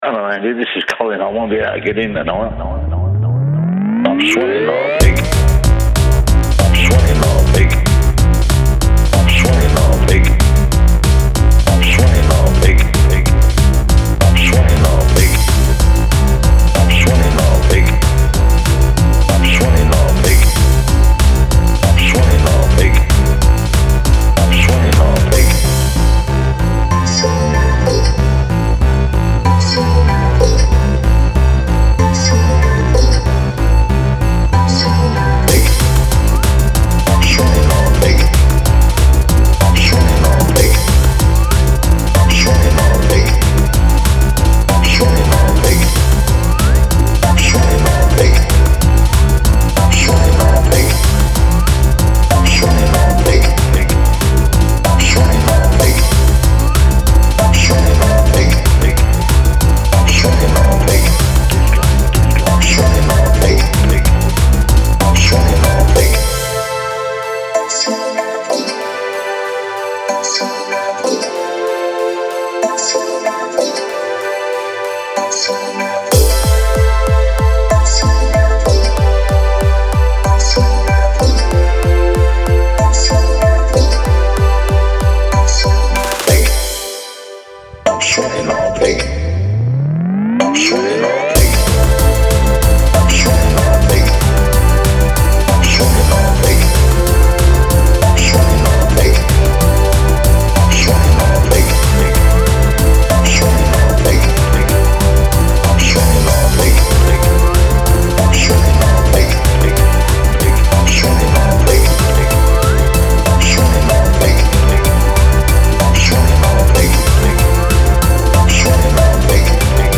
Style Style EDM/Electronic
Mood Mood Driving, Funny, Intense
Featured Featured Bass, Drums, Synth +1 more
BPM BPM 189
Anything that needs fast energy!